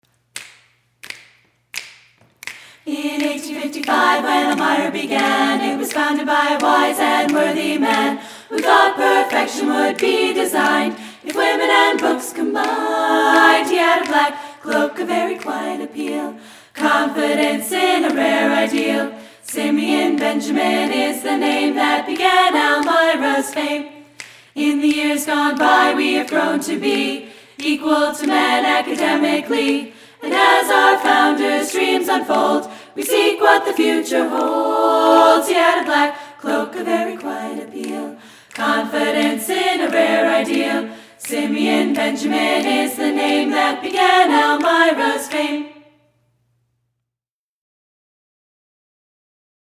Click on the "Listen (MP3)" button to hear the song performed by the EC Chiclettes where available.